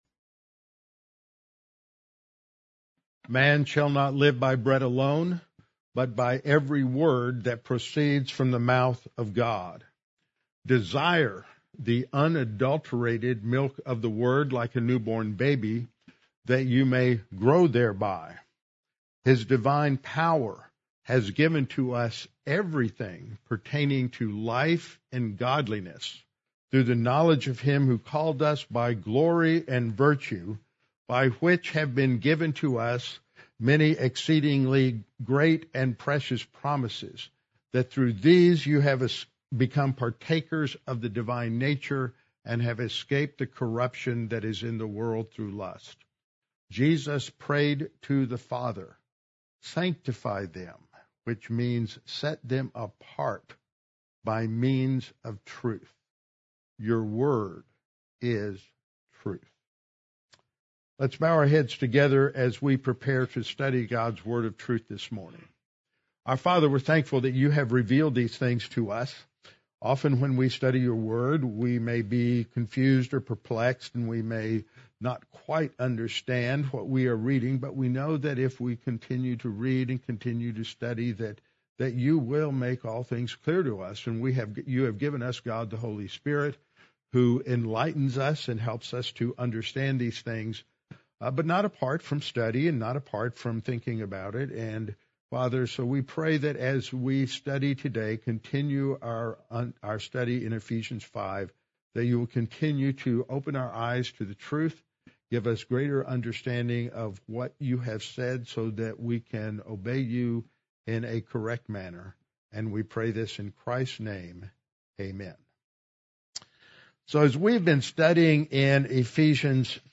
congregational singing workshop
The session focused on antiphonal singing and explored four ways to glorify God through song: speaking, teaching, singing,…